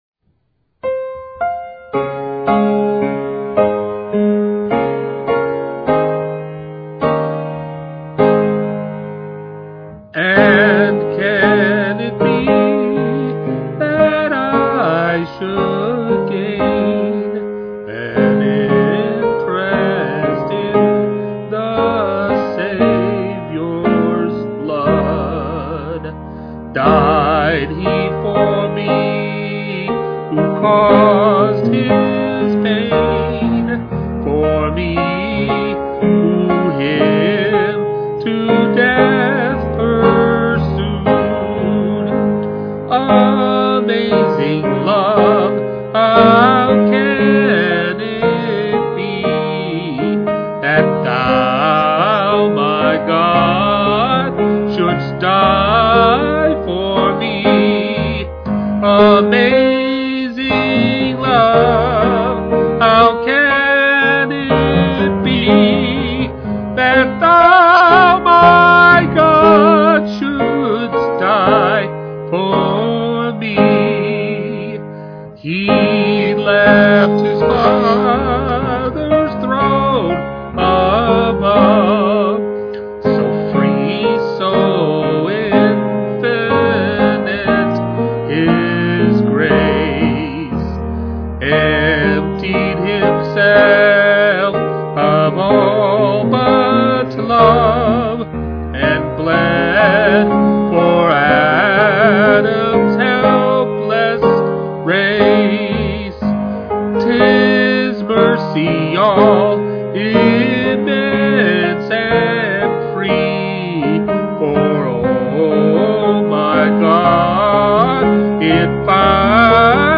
Hymn Singing
With piano